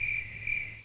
CRICKET.WAV